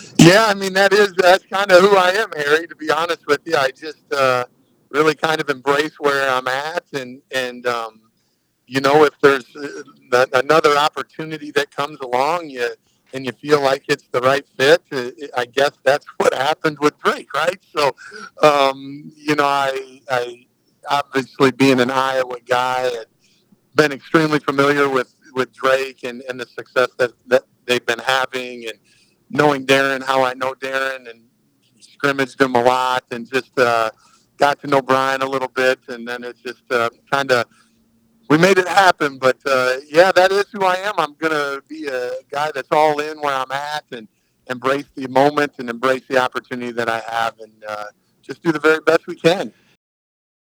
During our interview he repeatedly talks about other people.